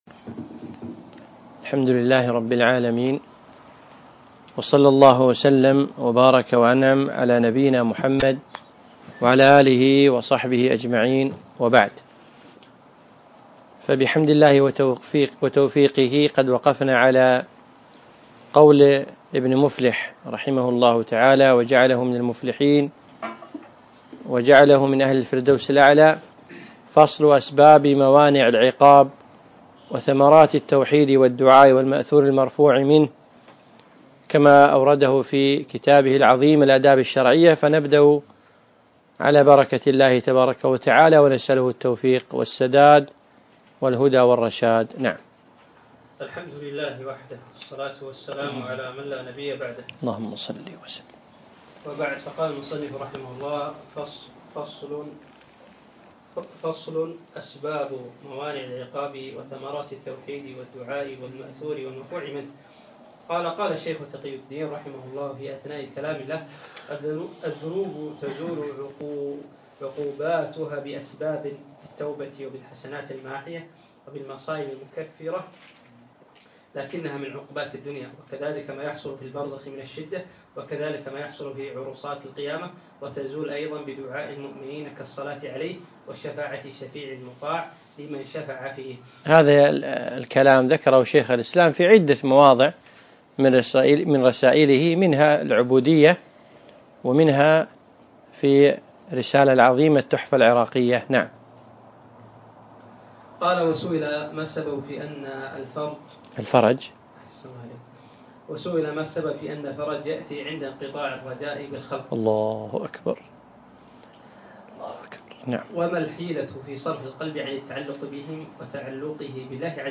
الدرس الثاني عشر